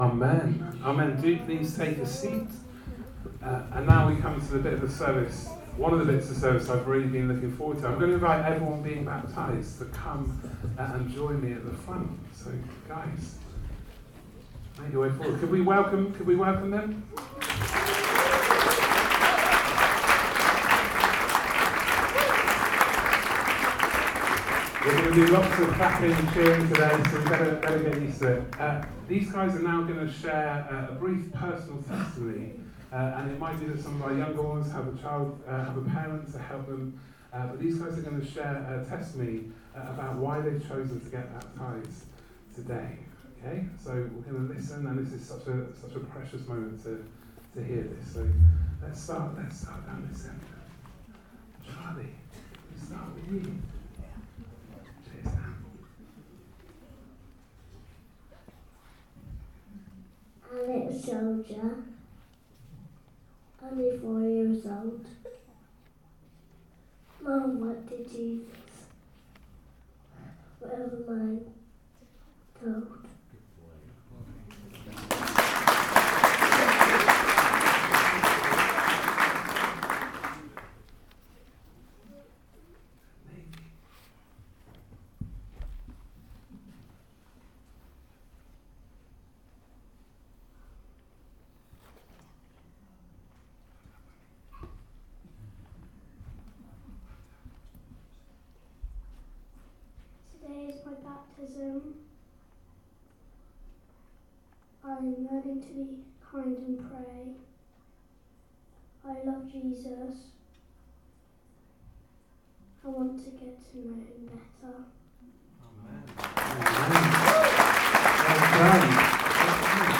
Baptisms! Testimonies, bible reading and sermon